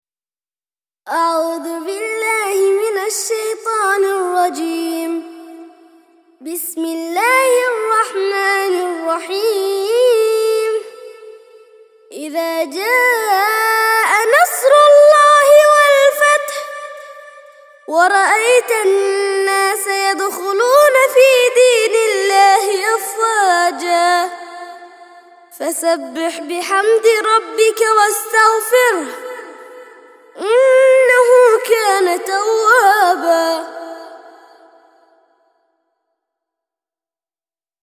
110- سورة النصر - ترتيل سورة النصر للأطفال لحفظ الملف في مجلد خاص اضغط بالزر الأيمن هنا ثم اختر (حفظ الهدف باسم - Save Target As) واختر المكان المناسب